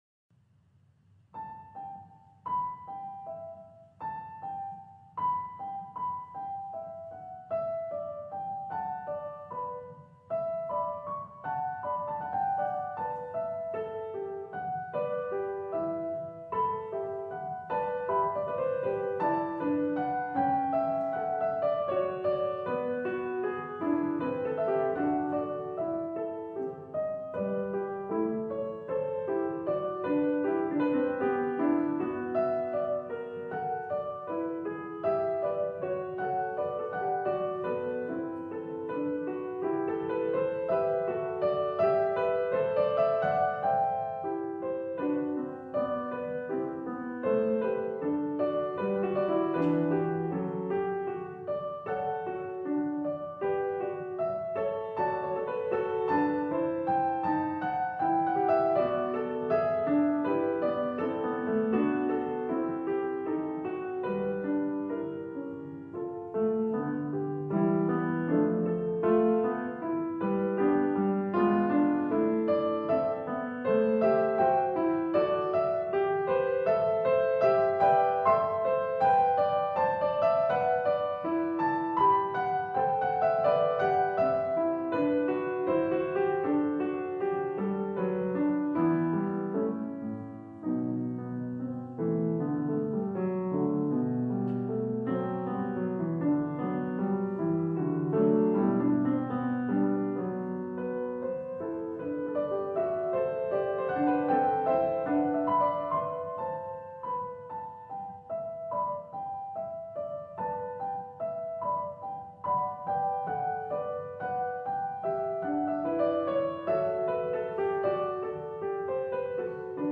스페인의 시계를 헌정했던 루이즈 크뤼피의 아들 장 크뤼피 중위를 추모하는 곡이다.[5] 독특한 리듬의 주제를 가지고 있으며, 중간부터 역행 푸가가 나타난다. 마지막에는 3성부가 나타난다.